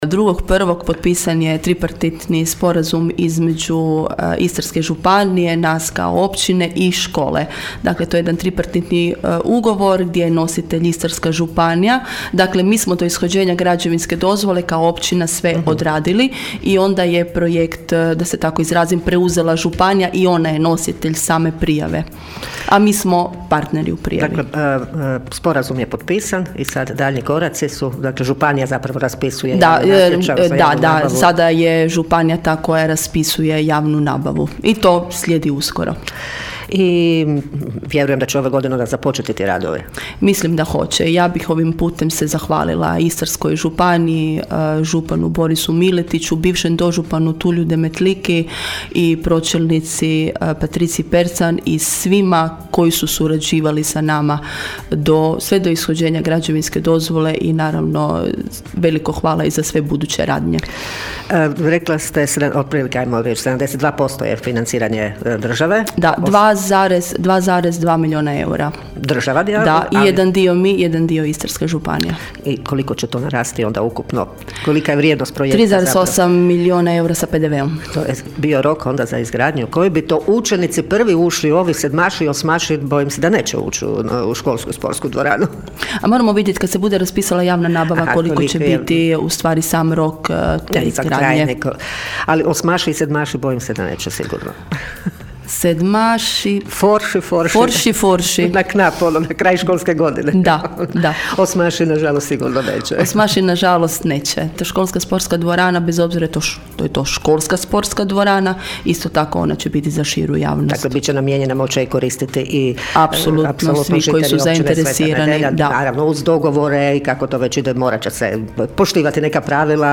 ton – Irene Franković).